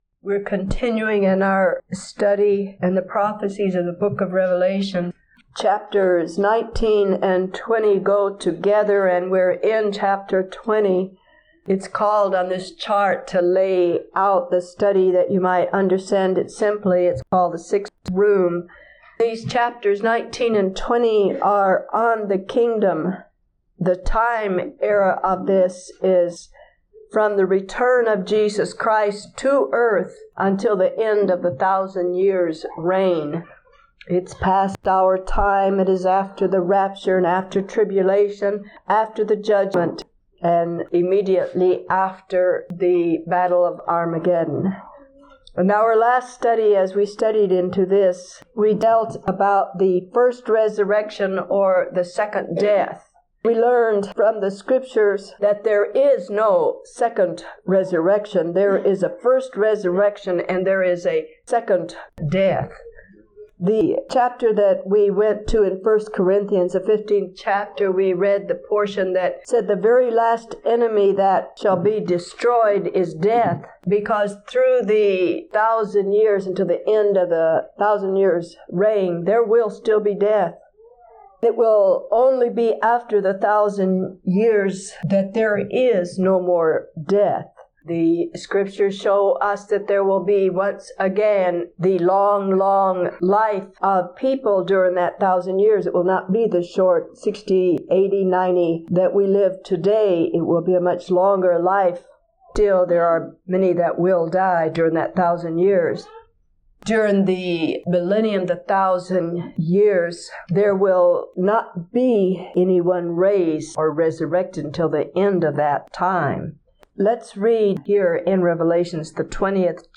May 6, 1987 – Teaching 65 of 73